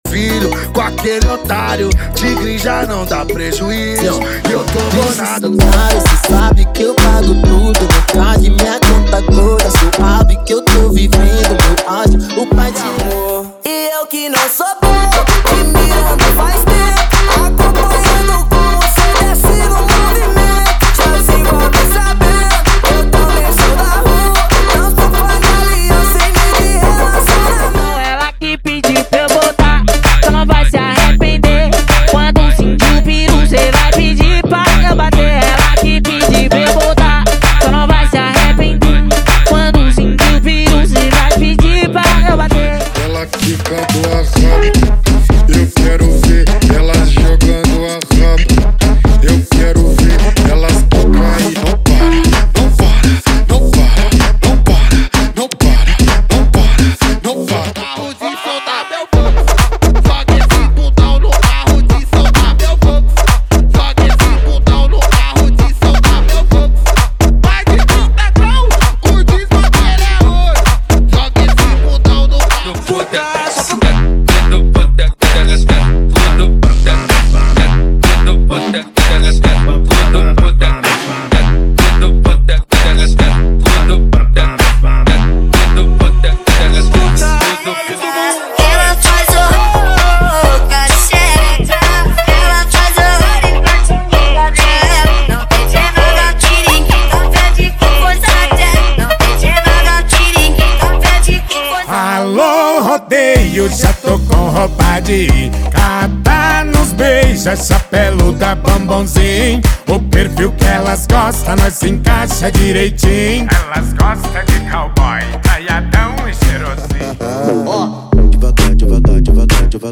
Os Melhores Eletro Funk do momento estão aqui!!!
• Sem Vinhetas
• Em Alta Qualidade